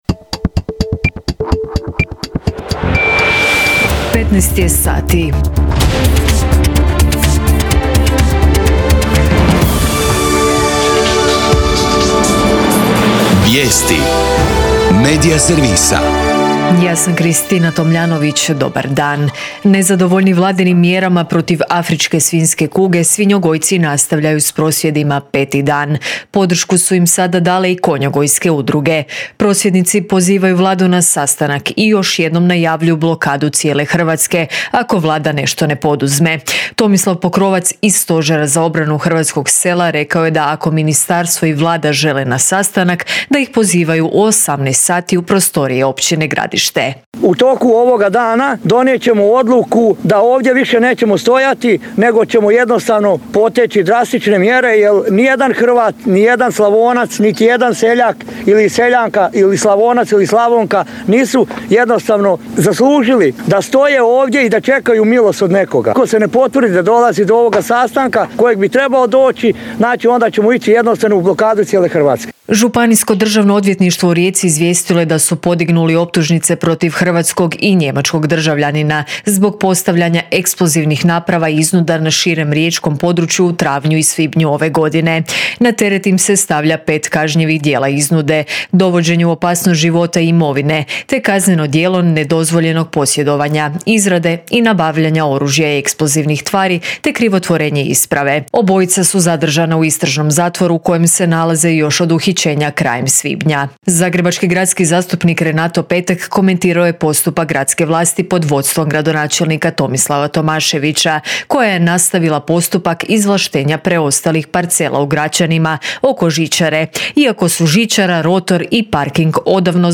VIJESTI U 15